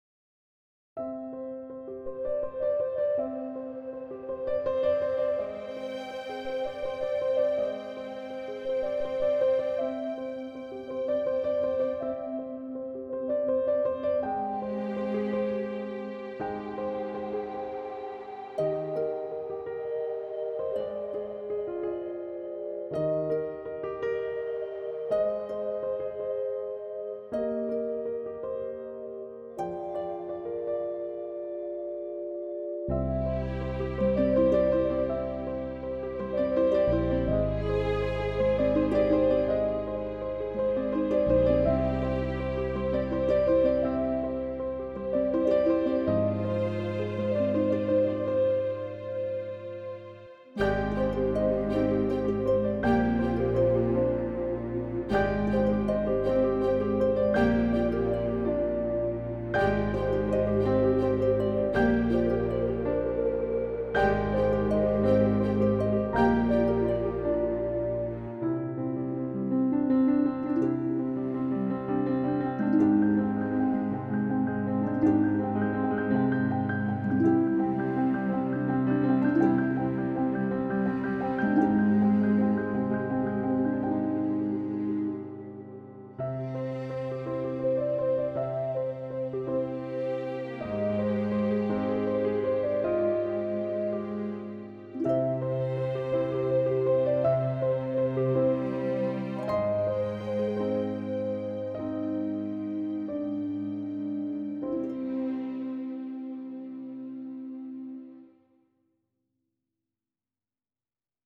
"Energetic Pop"